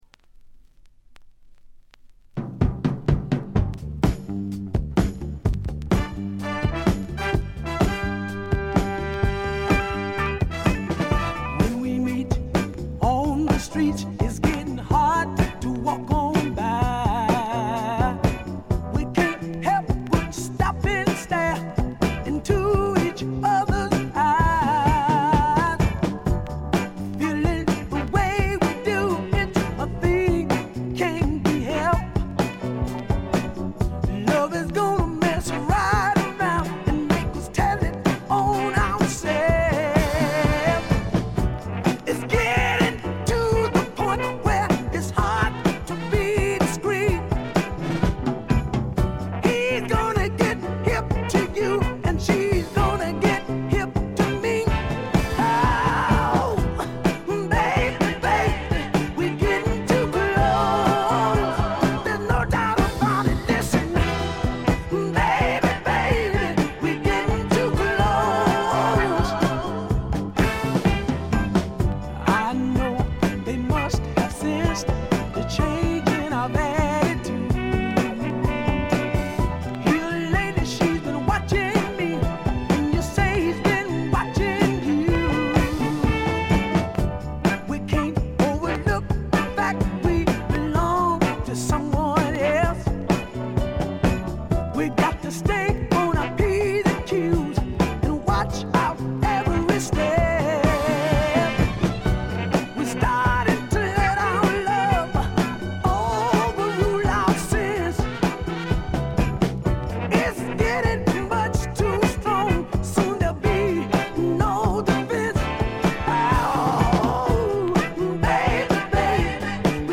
栄光の60年代スタックス・ソウルの正統なる継承者とも言えるディープなヴォーカルと適度なメロウさがたまらないです。
試聴曲は現品からの取り込み音源です。